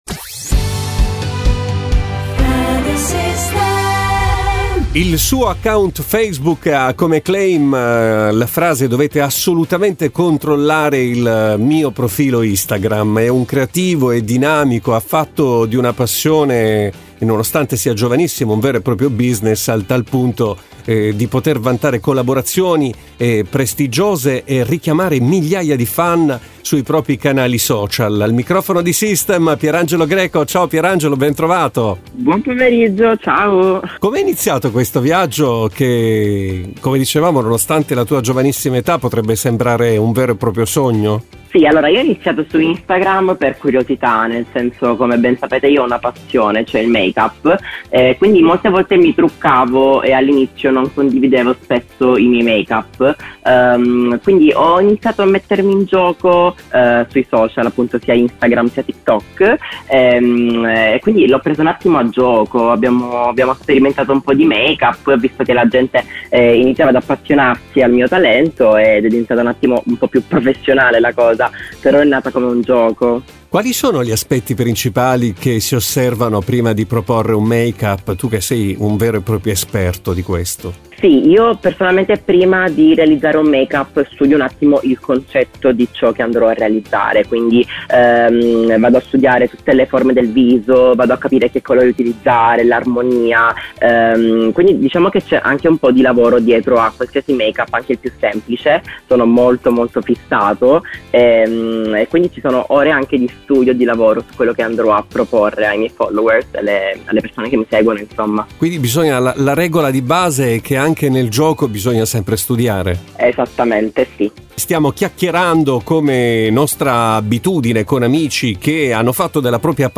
Radio System | Podcast – Un’esperienza nata quasi per gioco, poi diventata percorso professionale e artistico, fino a sfociare in una vera e propria community social.